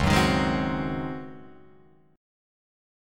C#M7sus2sus4 Chord